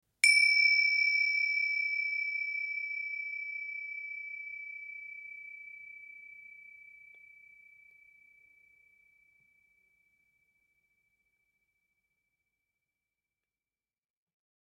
Download Bell sound effect for free.
Bell